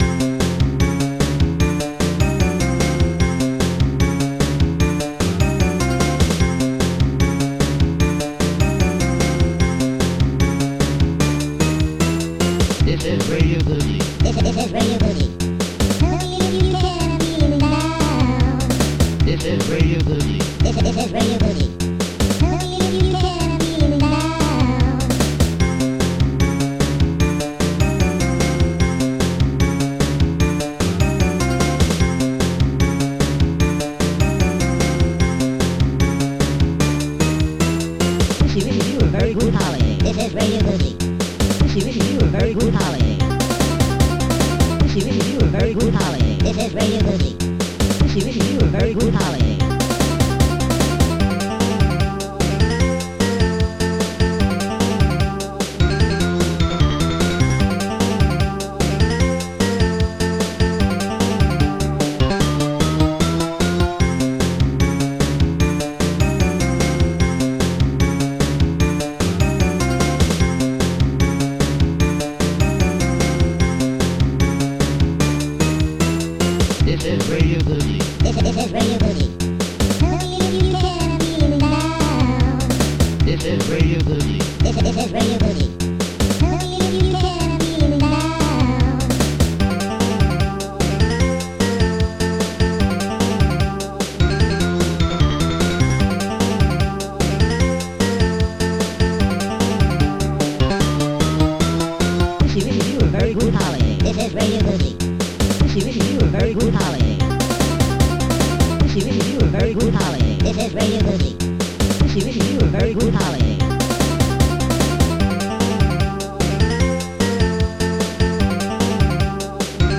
Protracker Module
d10bassdrum1 d10snare3 d10cup d10clohihat1 bass-3 pwyavgh-voice tirp-voice e-piano-2 digital-4 hmiycifd-voice